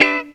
GTR 53 EM.wav